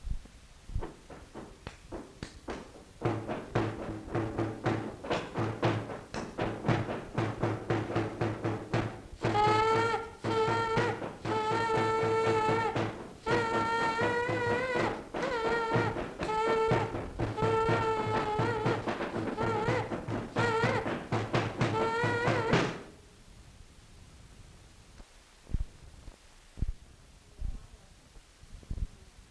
Instrumental. Sifflet végétal
Genre : morceau instrumental
Instrument de musique : tambour ; sifflet végétal
Ecouter-voir : archives sonores en ligne